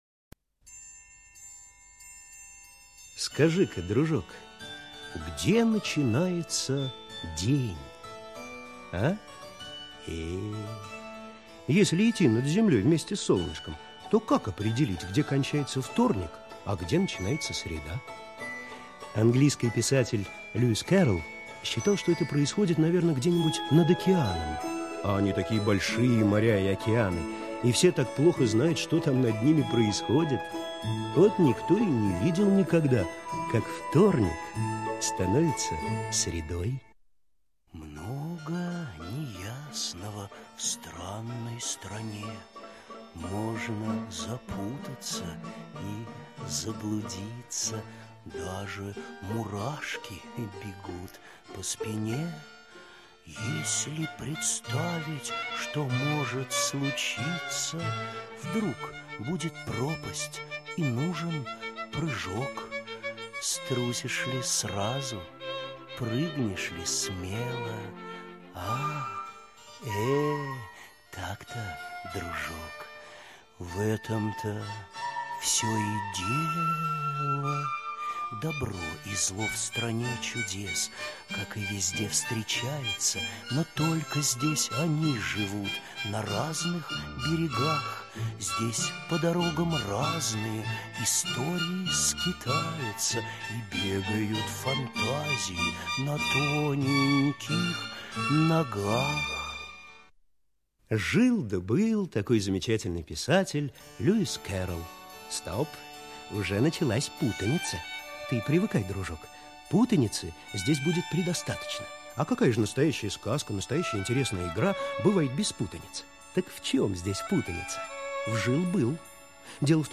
Алиса в стране чудес - аудиосказка Кэрролла - слушать